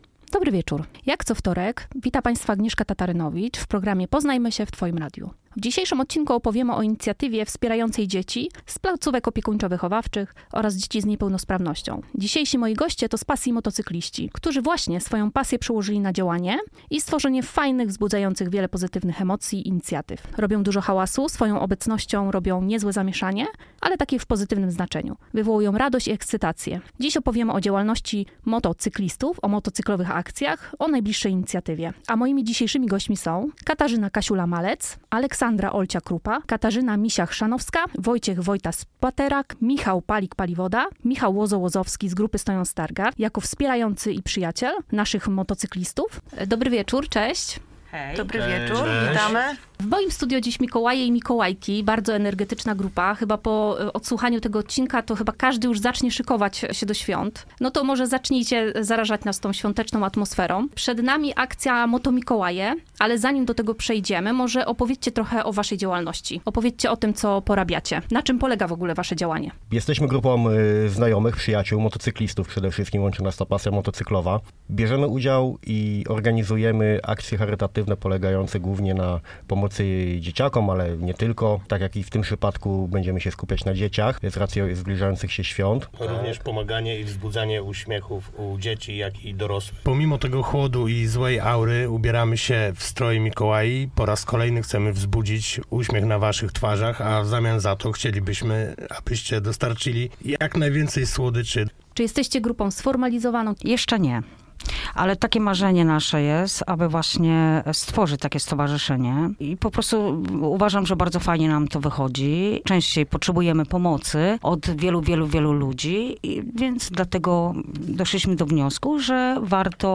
Dzisiejszymi gośćmi będą motocykliści wraz z przyjaciółmi